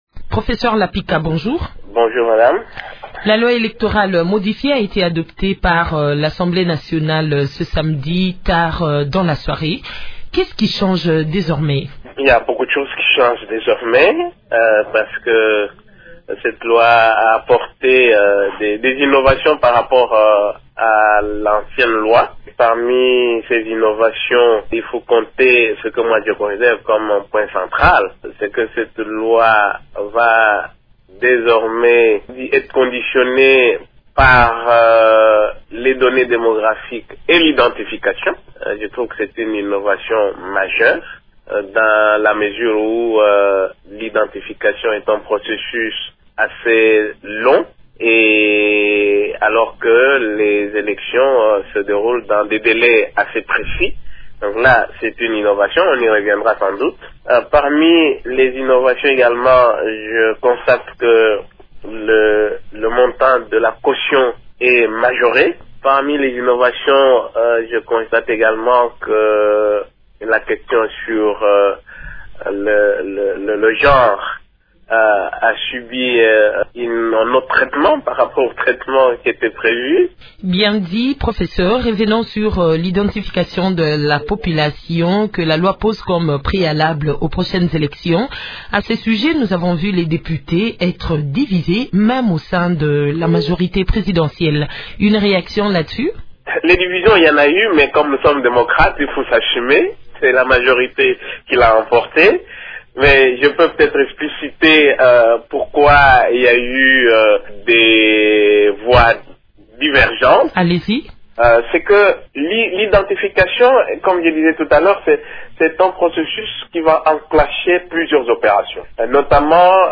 Invité de Radio Okapi, le député de la Majorité, Bruno Lepika, estime que cette loi corrige les lacunes observées lors des précédentes élections. Parmi les innovations, il parle notamment de la place des données démographiques et l’identification des électeurs, mais aussi de la majoration des cautions pour les postulants, sans oublier la question sur le genre.